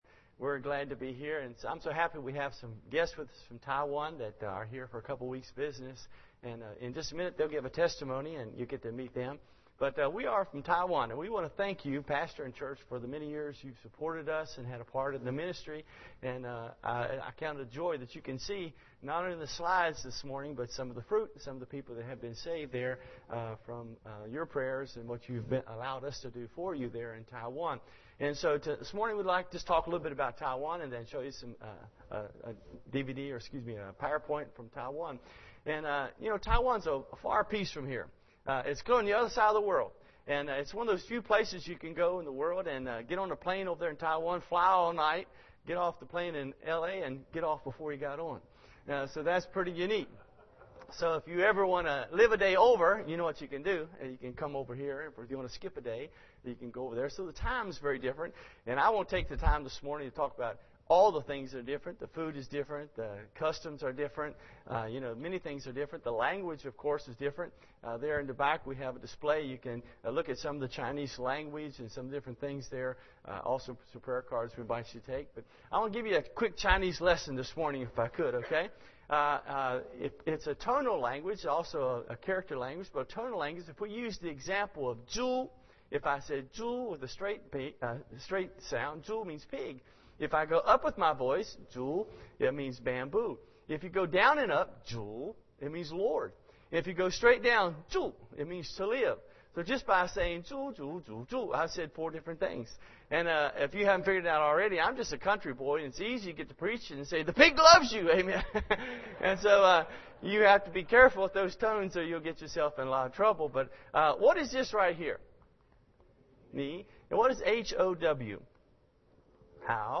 Service Type: Sunday School Hour